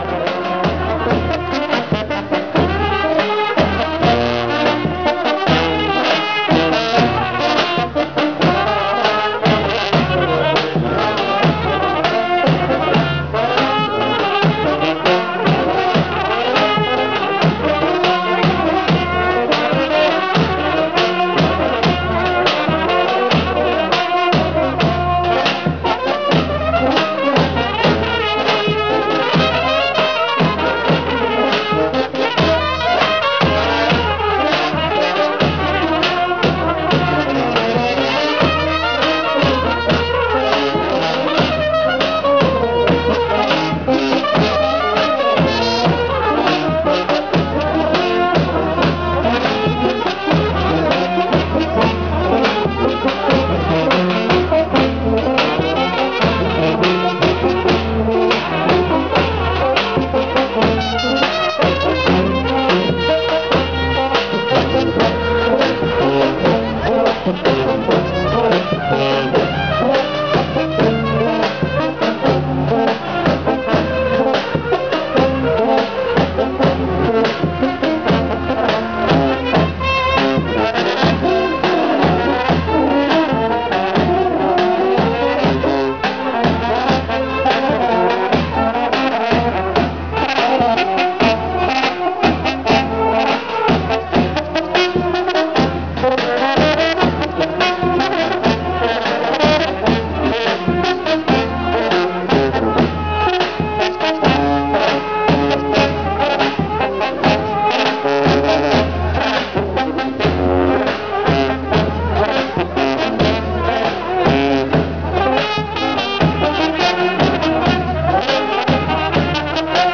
auf einer serbischen Hochzeit dar. Die Musiker_innen spielen für Serb_innen die gleiche Melodie wie für Rom_nja, jedoch in schnellerem Tempo und mit weniger kunstvoller melodischer Improvisation. Preševo, Serbien, August 2011.